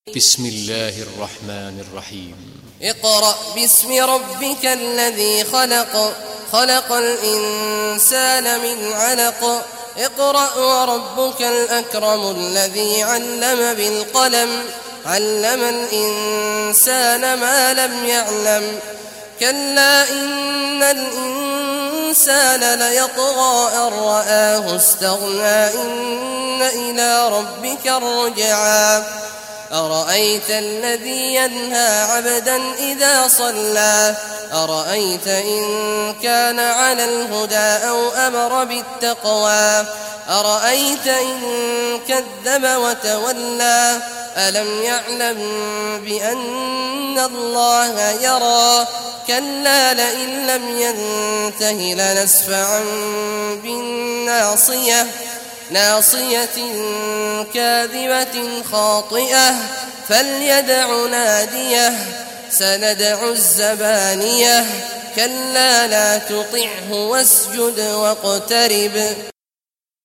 Surah Al-Alaq Recitation by Sheikh Awad al Juhany
Surah Al-Alaq, listen or play online mp3 tilawat / recitation in Arabic in the beautiful voice of Sheikh Abdullah Awad al Juhany.